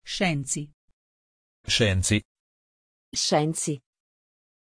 Pronunciation of Shenzi
pronunciation-shenzi-it.mp3